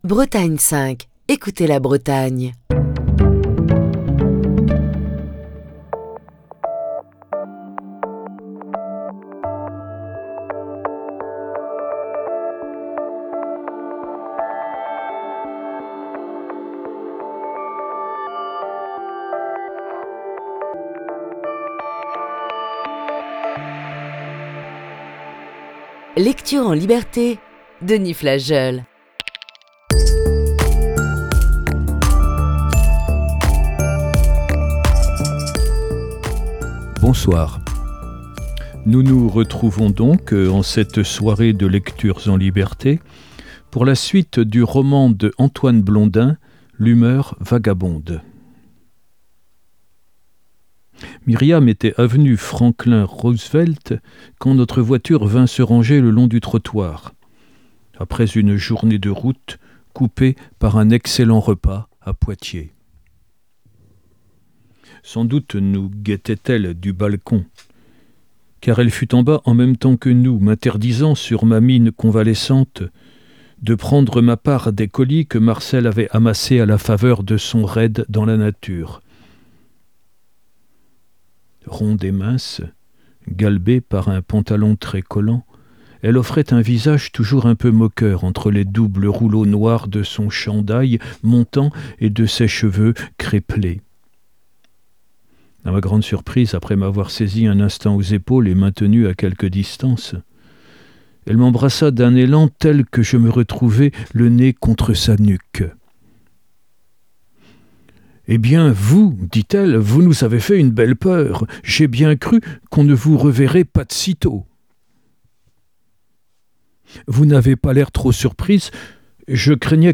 lit le roman d'Antoine Blondin "L'humeur vagabonde"